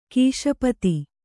♪ kīśapati